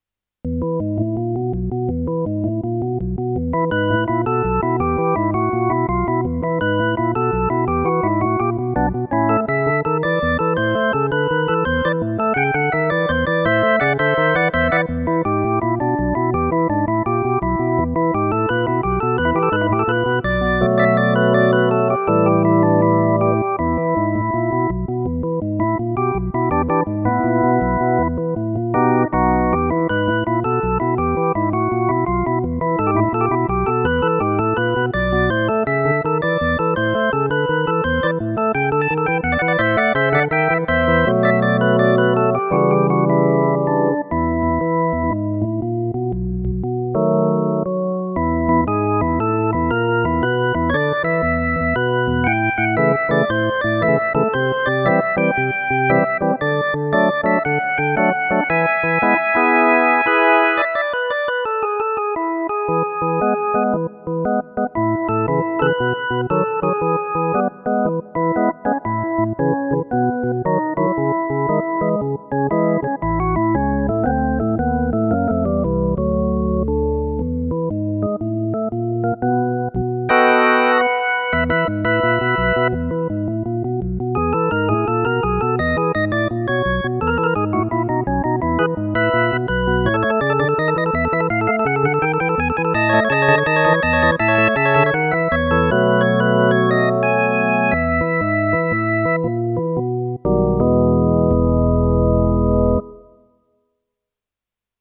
WAS DER AFFE AM MONTAG GESPIELT HAT (JAZZ ORGEL)